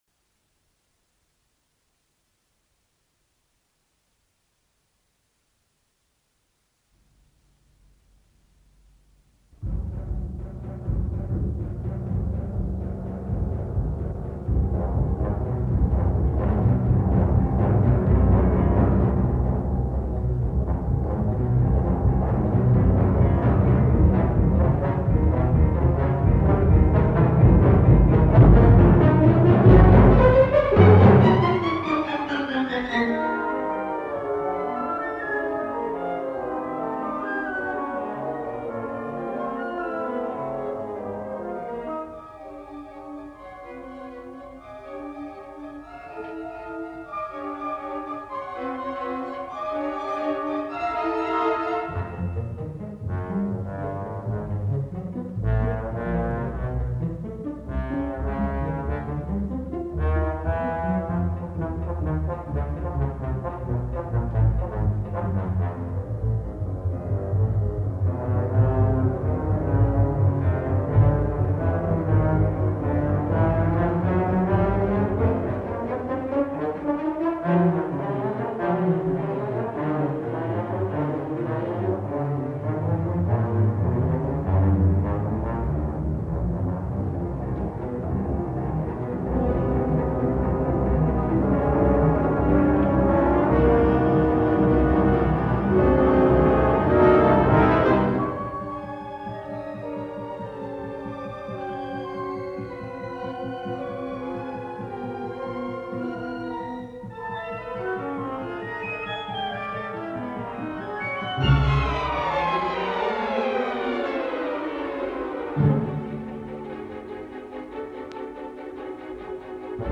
poema sinfonico